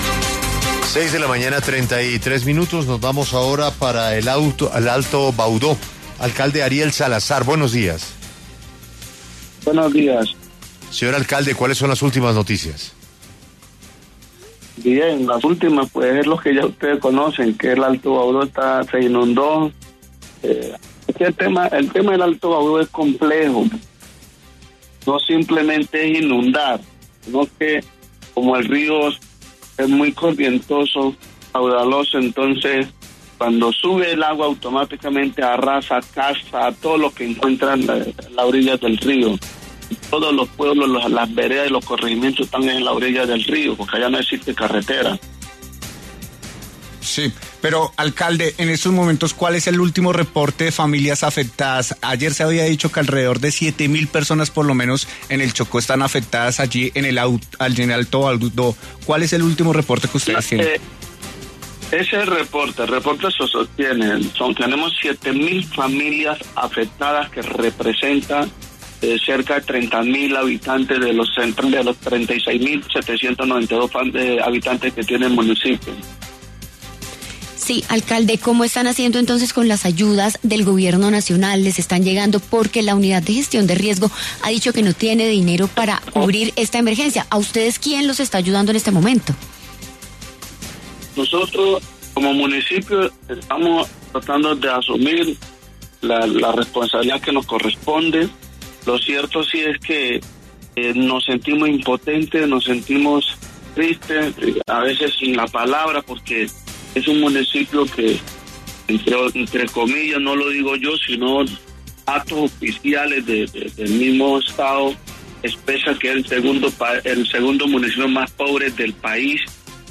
El alcalde del Alto Baudó, Ariel Salazar, pasó por los micrófonos de La W para hablar sobre cómo vive la población estos días de crisis.